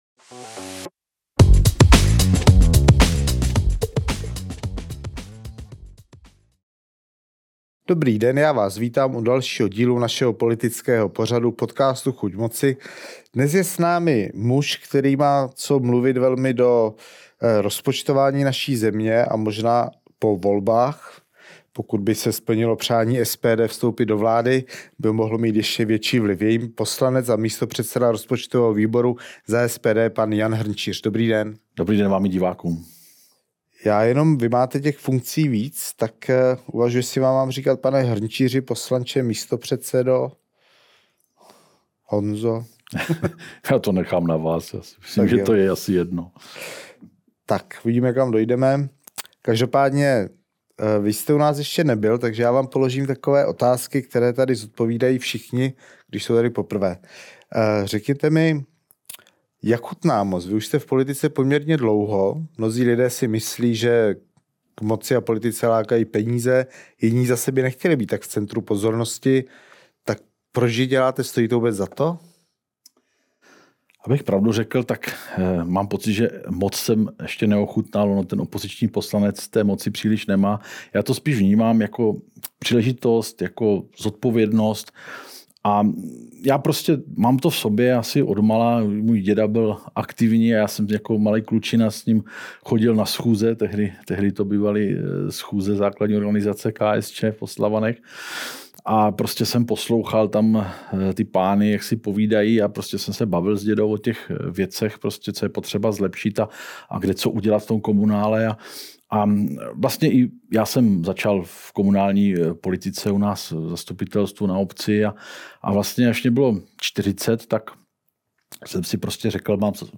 Natočeno ve studiu Datarun!